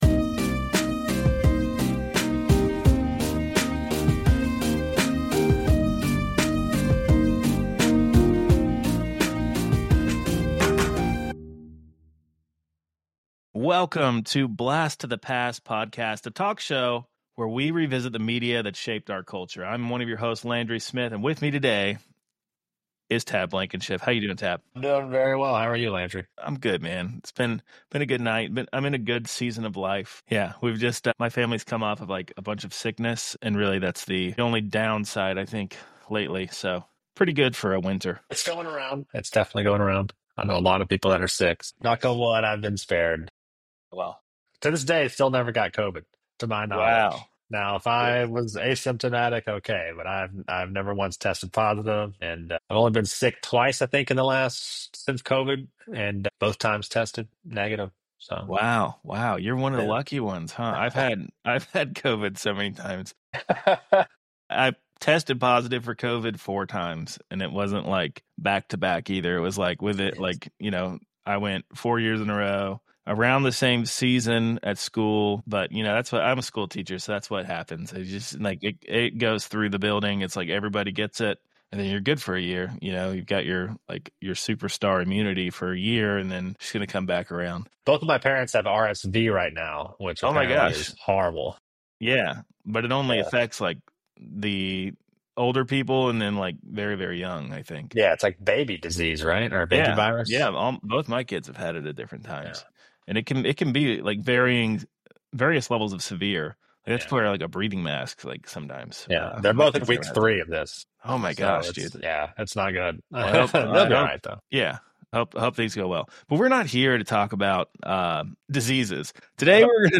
They explore the film's cultural impact, character performances, and underlying themes of poverty and self-destruction. The conversation highlights the film's legacy, the significance of its iconic lines, and the emotional depth brought by the cast, particularly Robin Williams. The hosts also reflect on the film's relevance in today's cinematic landscape and share their personal ratings.